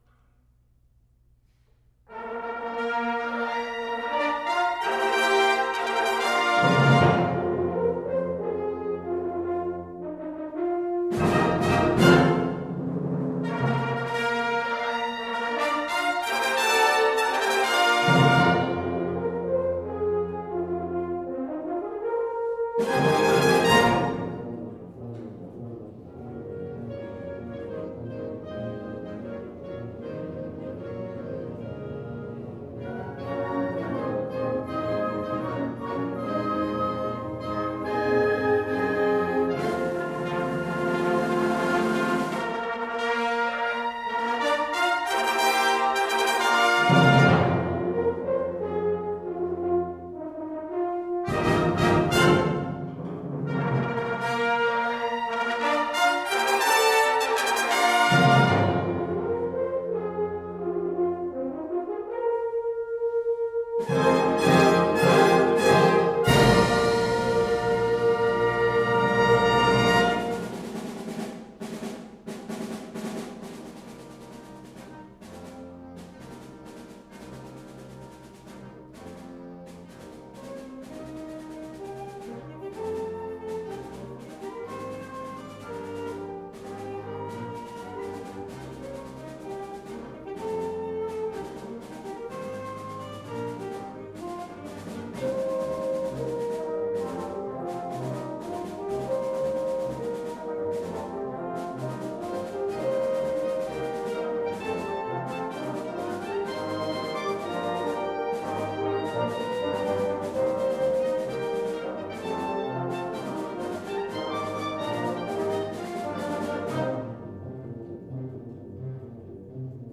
Listen to the Band – University Heights Symphonic Band
Here’s a selection of University Heights Symphonic Band performances that demonstrate the ensemble’s artistry and varied repertoire.
An American Elegy (Frank Ticheli), recorded at St. Alban Episcopal Church, Cleveland Heights, Ohio, June 23, 2016